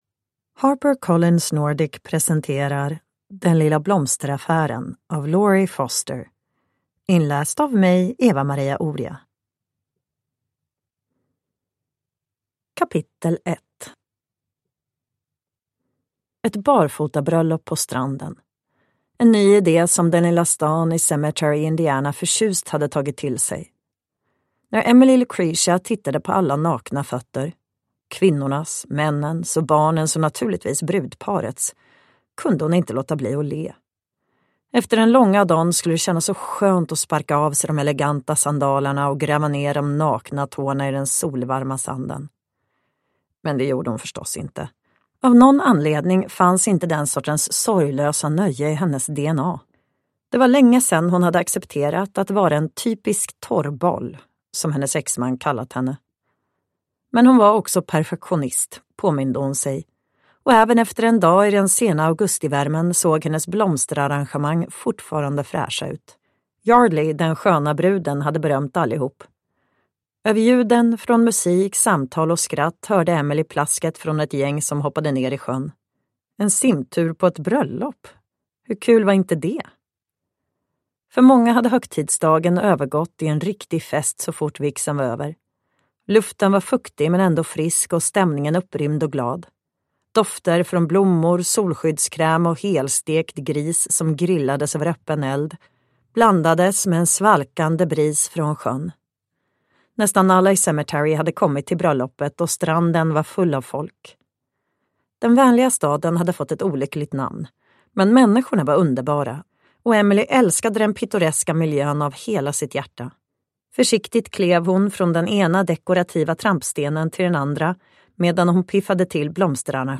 Den lilla blomsteraffären (ljudbok) av Lori Foster